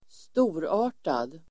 Uttal: [²st'o:ra:r_tad]